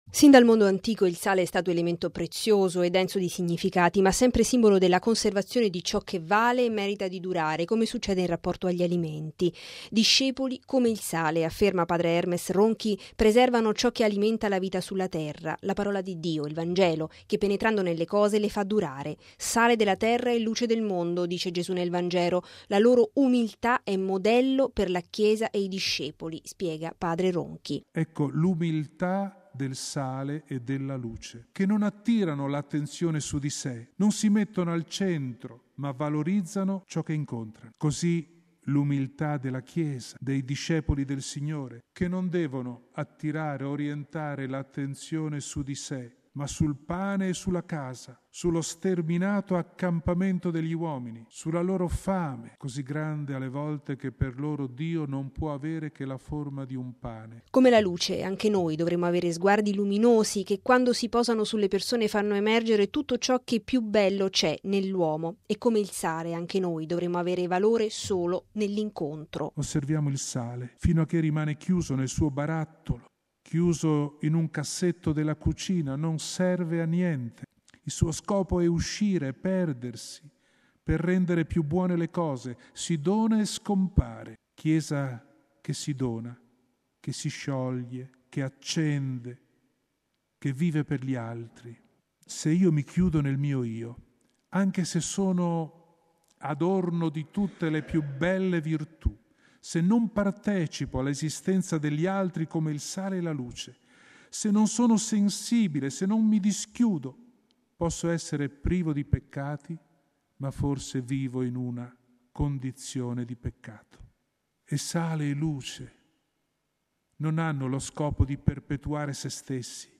Seconda giornata di esercizi spirituali per il Papa e la Curia Romana nella Casa del Divin Maestro di Ariccia.
Questo pomeriggio si è partiti dal testo di Matteo :”Voi siete il sale della terra. Ma se il sale perde sapore con che cosa lo si renderà salato?”. Il servizio